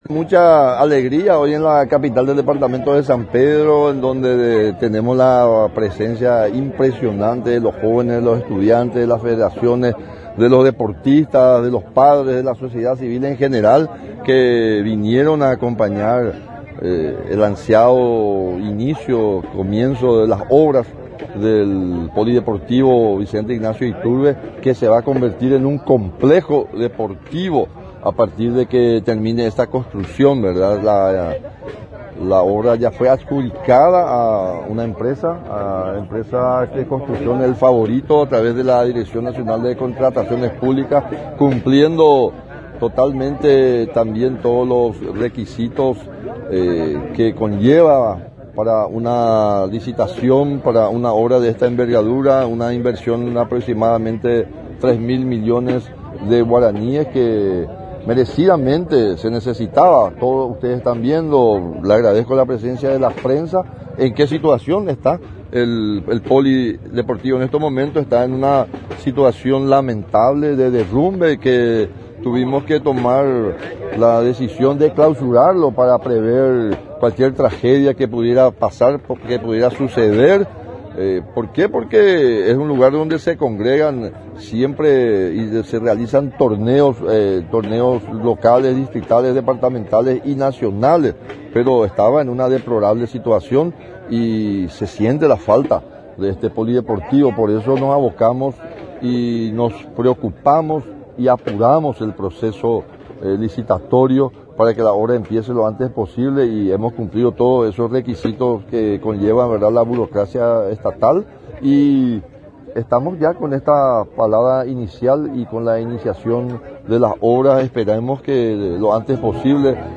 NOTA: FREDDY DECCLESIIS-GOBERNADOR DE SAN PEDRO.